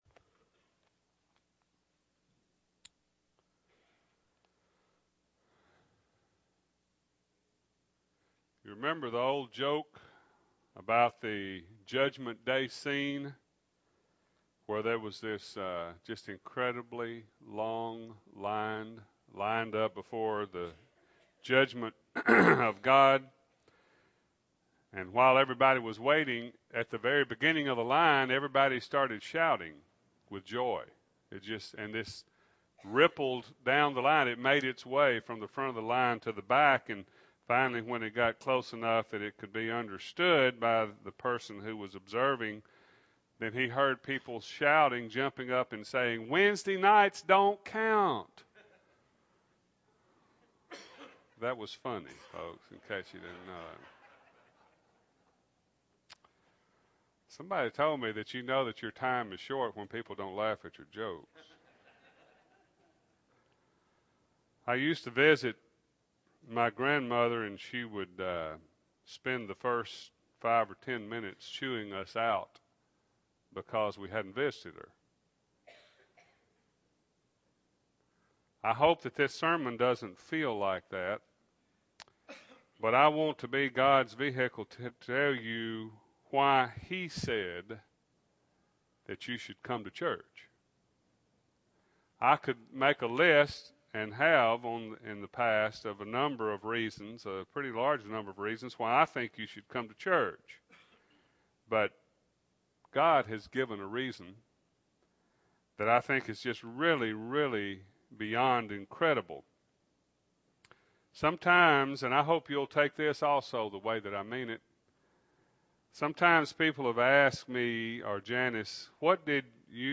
2008-02-24 – Sunday AM Sermon – Bible Lesson Recording